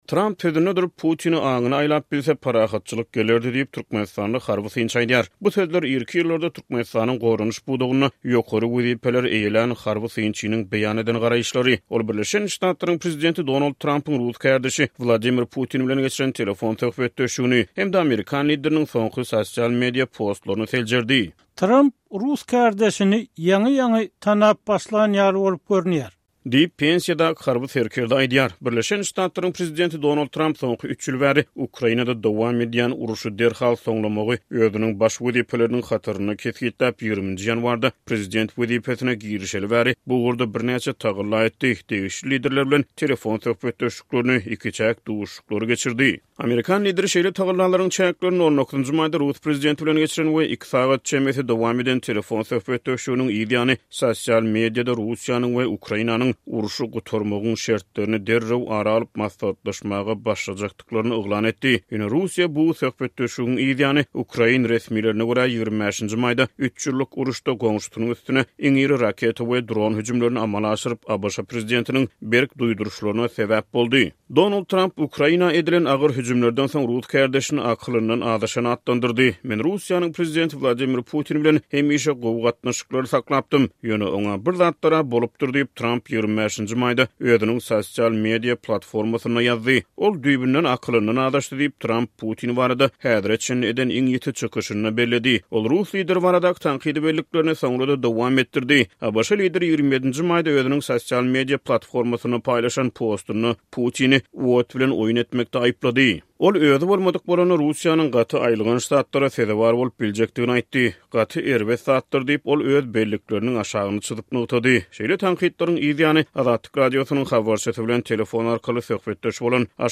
Diňle: Tramp sözünde durup, Putini 'aňyna aýlap bilse' parahatçylyk gelerdi – türkmenistanly harby synçy